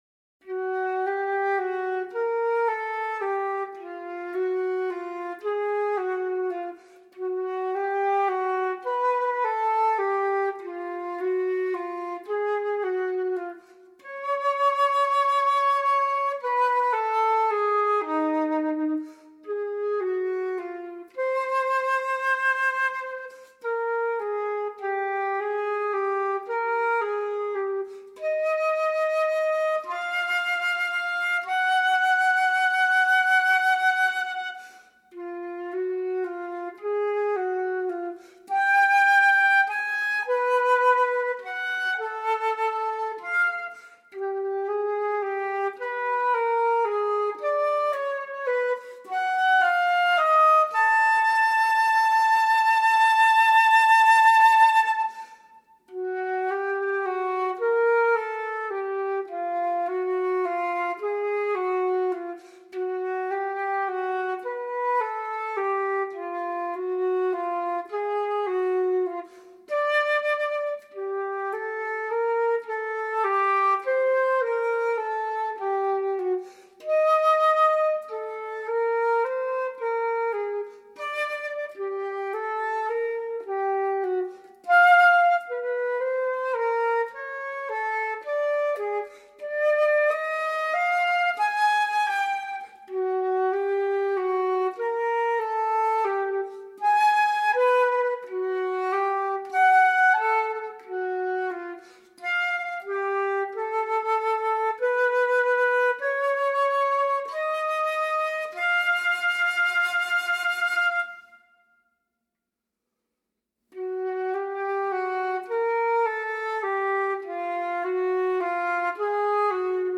für Flöte solo